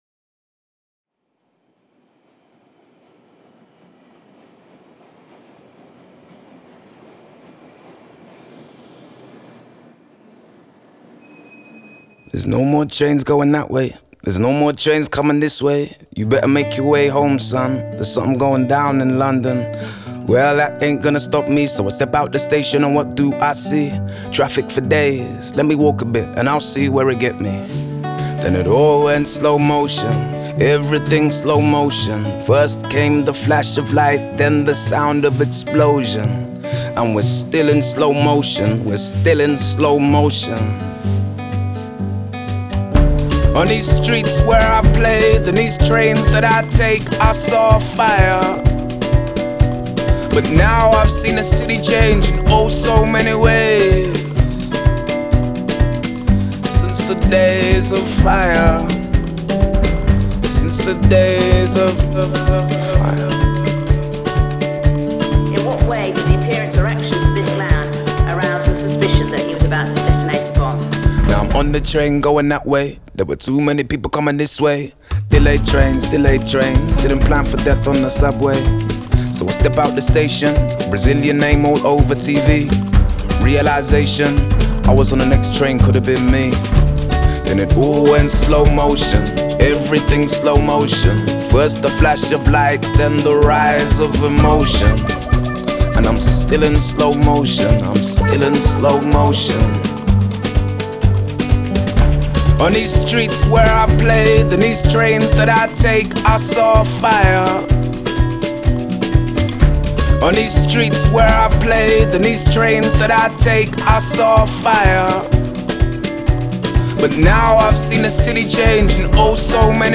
Categories Hip Hop , Pop